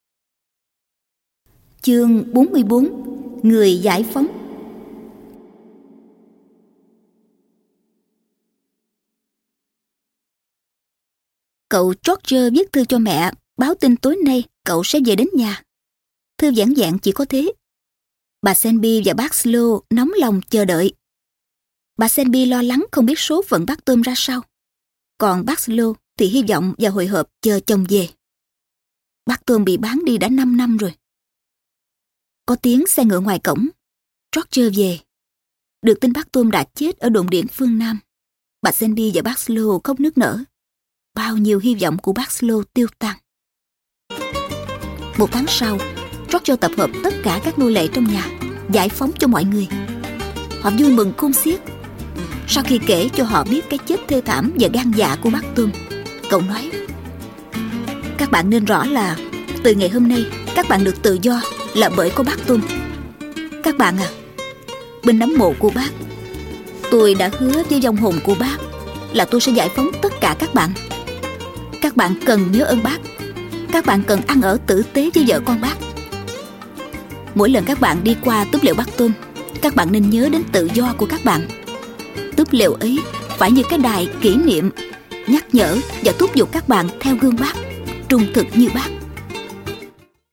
Sách nói | Túp lều bác Tom – Harriet Beecher Stowe - phần 31->45